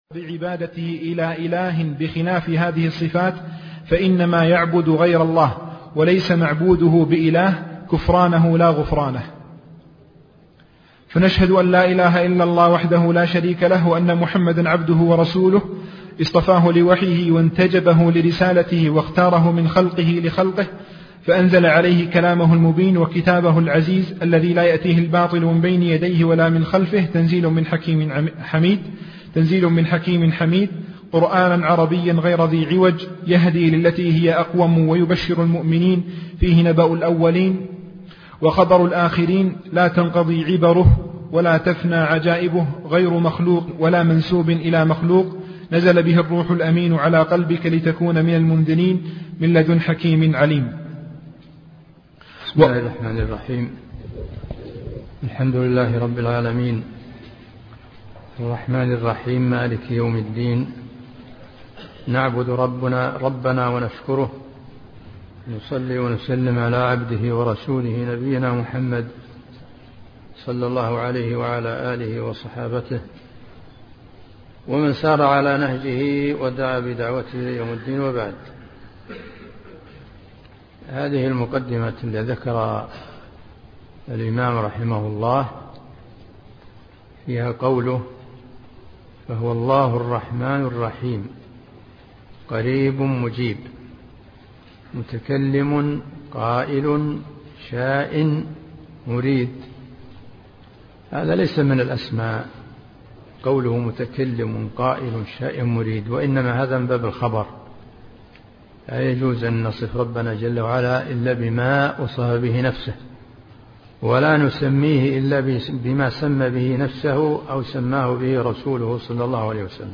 عنوان المادة الدرس (1) شرح رسالة في الصفات تاريخ التحميل الخميس 9 فبراير 2023 مـ حجم المادة 30.43 ميجا بايت عدد الزيارات 263 زيارة عدد مرات الحفظ 115 مرة إستماع المادة حفظ المادة اضف تعليقك أرسل لصديق